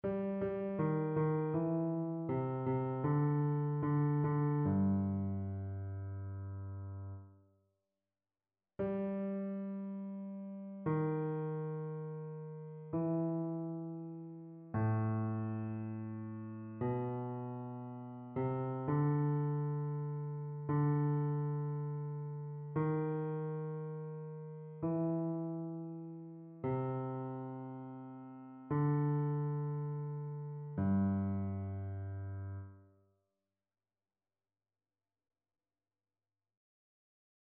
Basse
annee-a-temps-pascal-3e-dimanche-psaume-15-basse.mp3